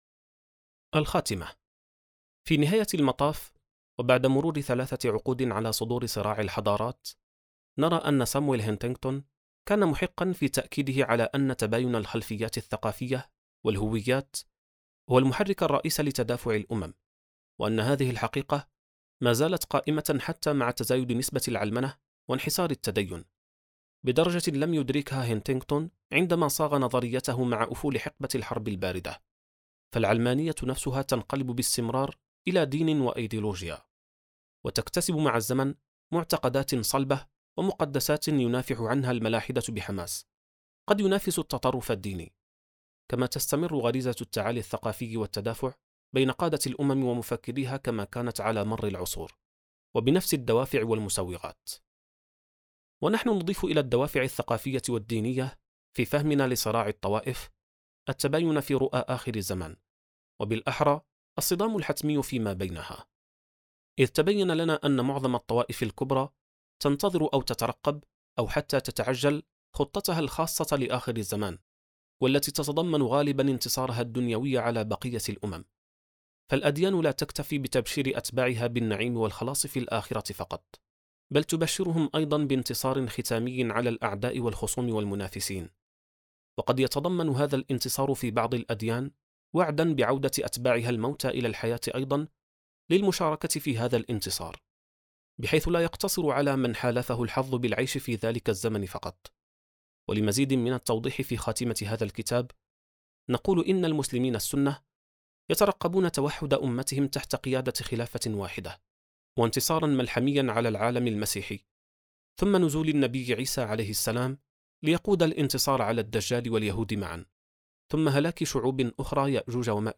كتاب صوتي | خارطة الطوائف (19\19): الخاتمة
كتاب “خارطة الطوائف” للكاتب أحمد دعدوش وبصوت منصة منطوق (19\19): الخاتمة.